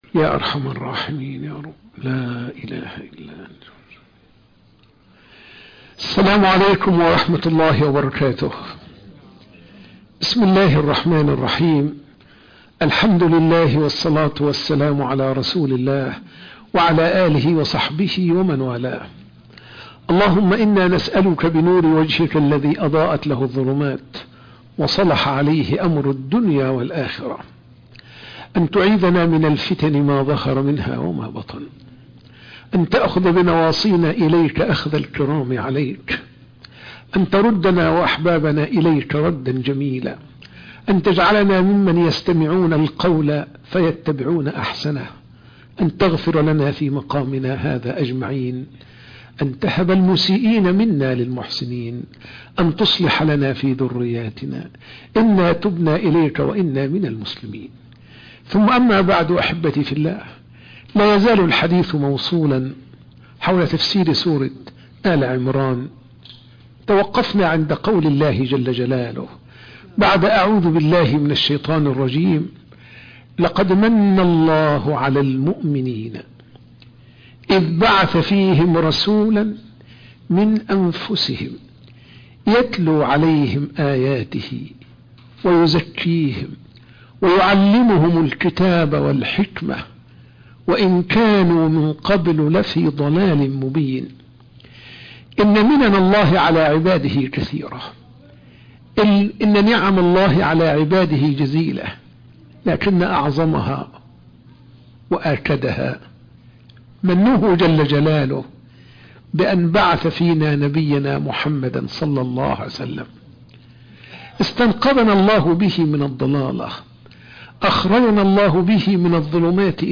درس بعد الفجر